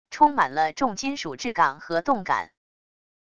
充满了重金属质感和动感wav音频